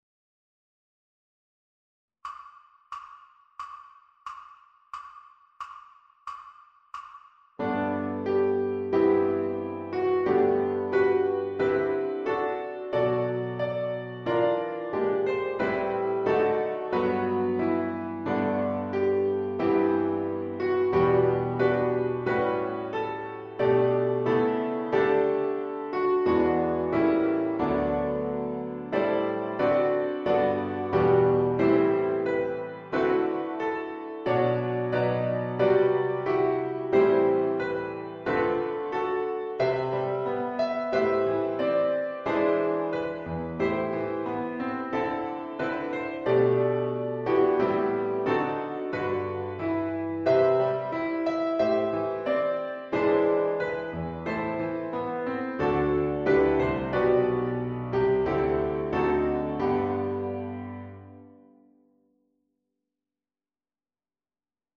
Hark-the-Herald-Angels-Sing-Piano-Christmas-1.3.0-ViolinSchool.mp3